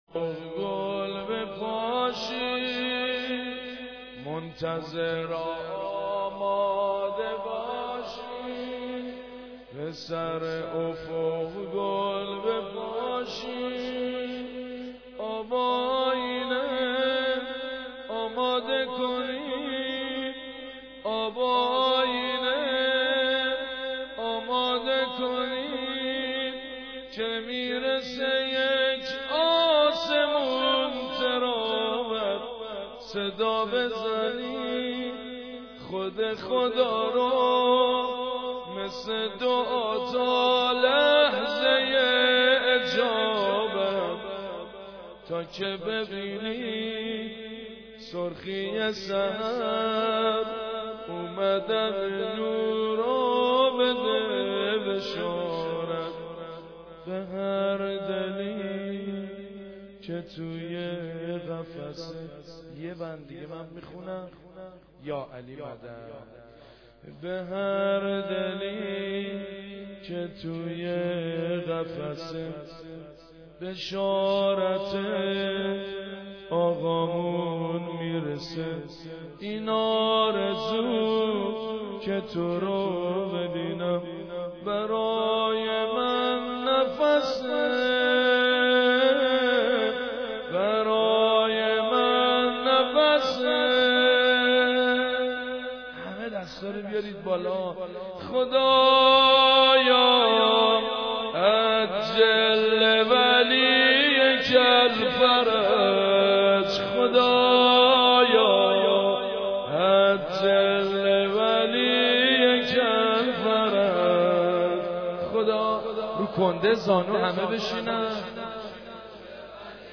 قاری : سيد مجيد بنى فاطمه